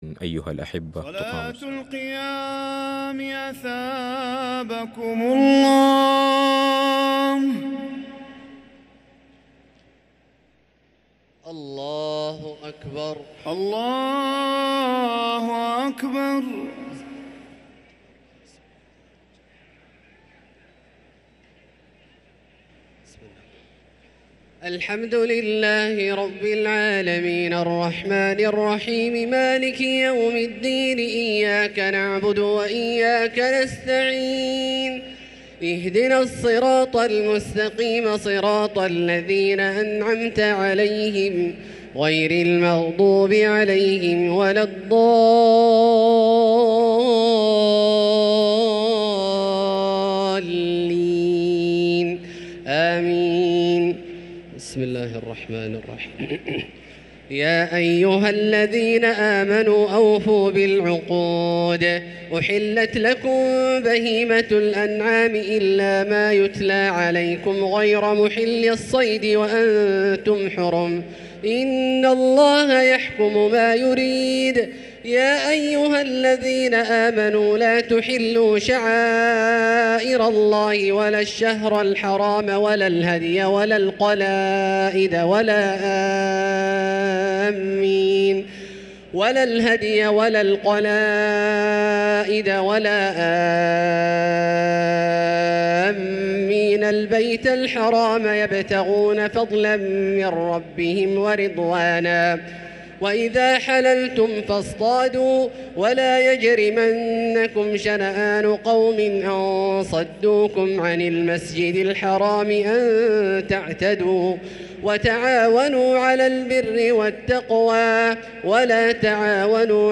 صلاة التراويح ليلة 8 رمضان 1444 للقارئ عبدالله الجهني - الثلاث التسليمات الأولى صلاة التراويح